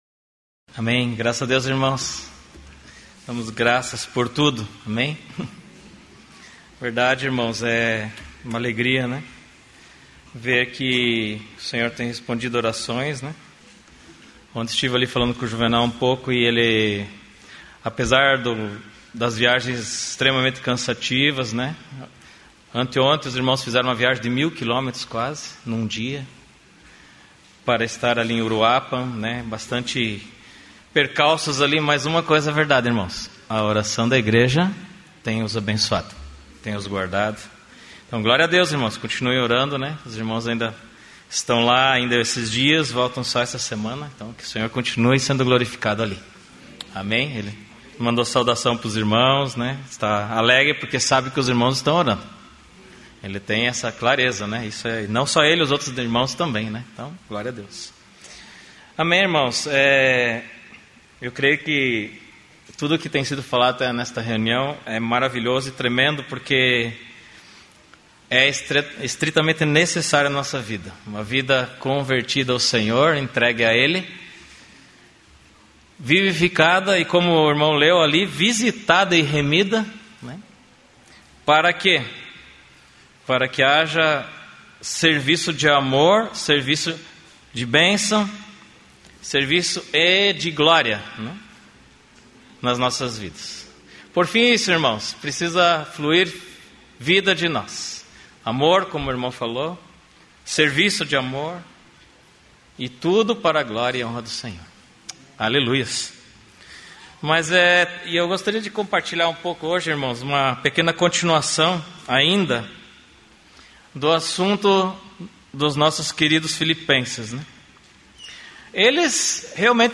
na reunião da igreja em Curitiba